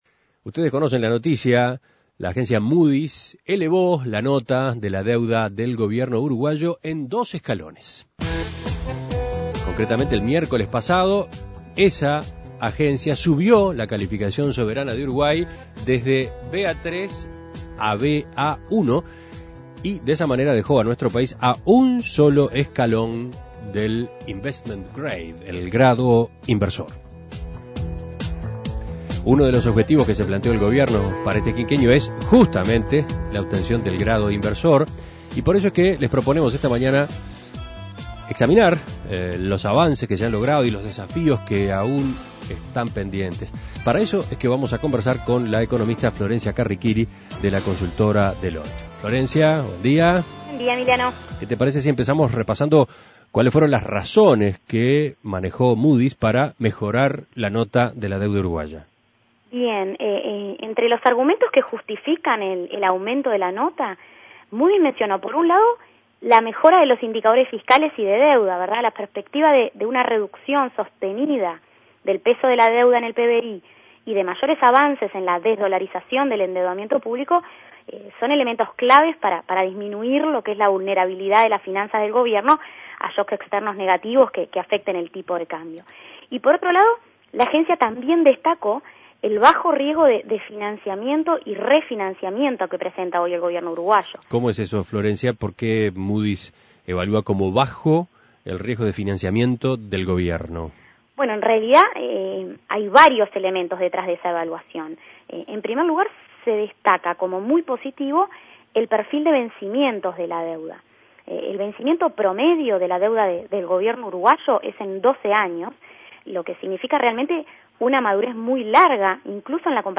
Análisis Económico Moody's elevó la nota de la deuda uruguaya y la dejó a un escalón del investment grade